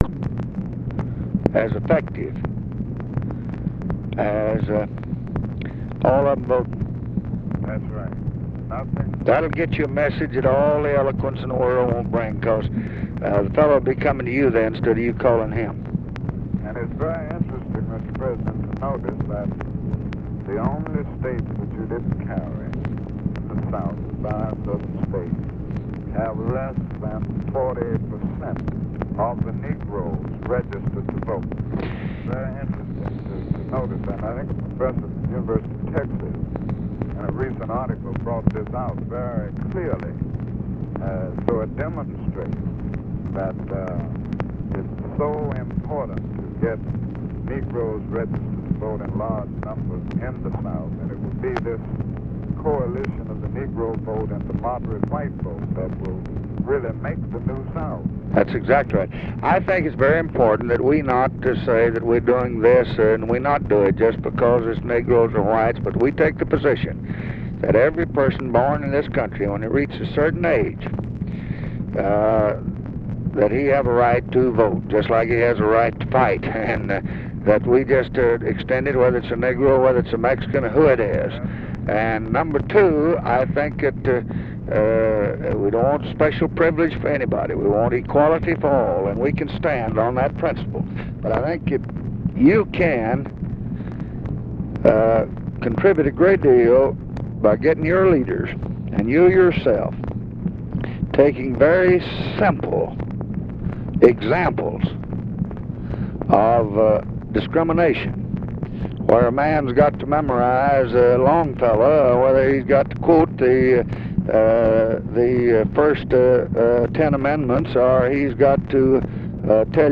Conversation with MARTIN LUTHER KING, January 15, 1965
Secret White House Tapes